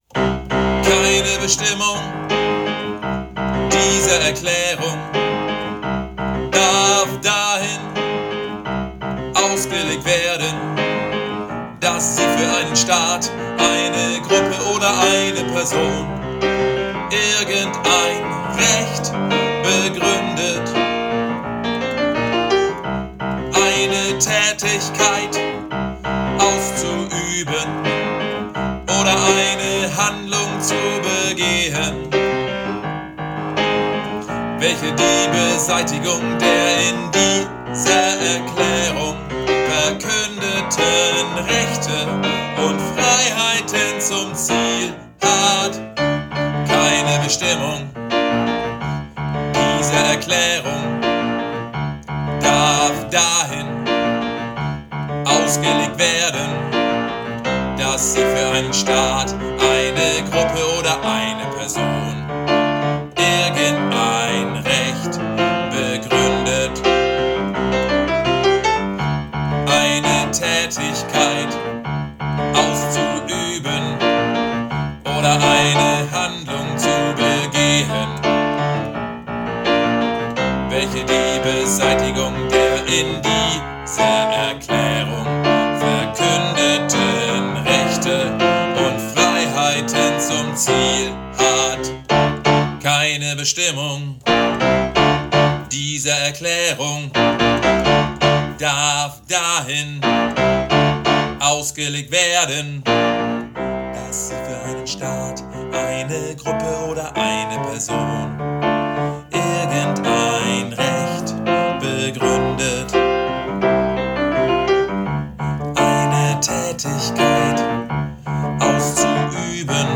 Übe-Dateien
ALT